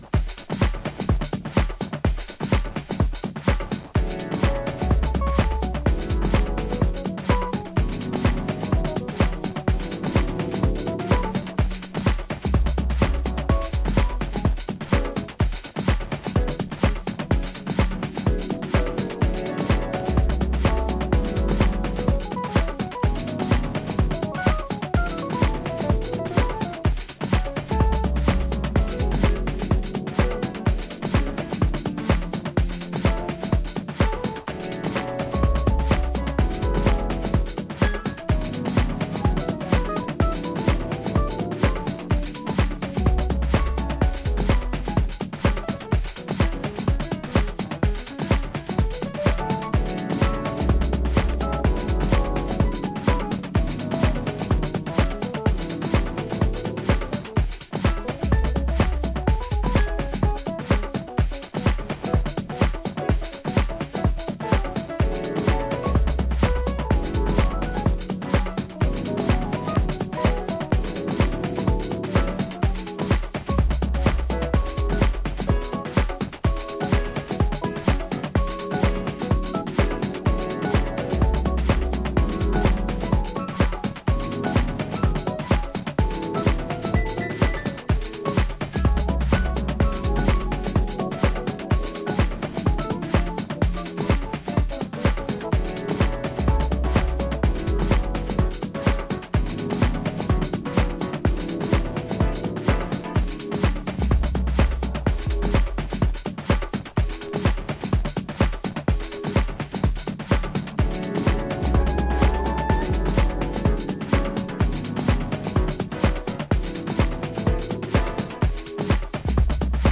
パーカッションとエレピが流麗に並走する